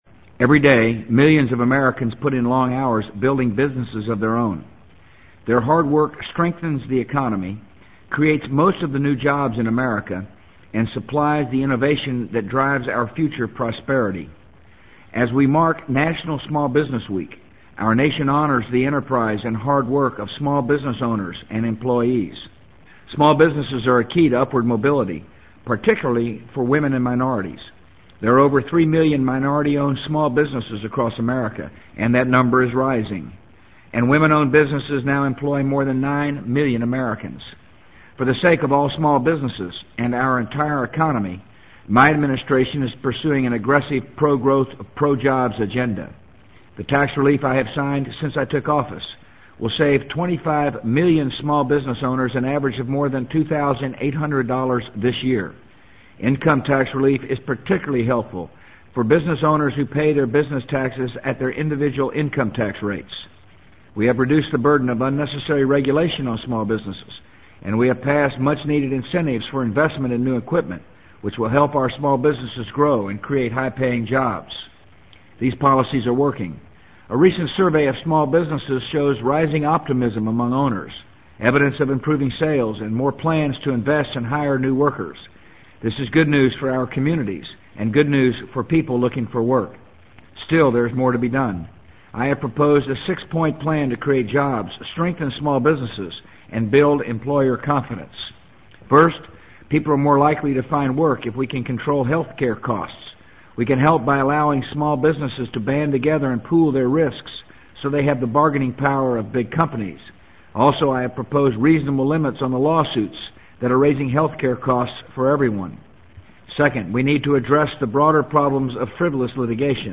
【美国总统George W. Bush电台演讲】2003-09-20 听力文件下载—在线英语听力室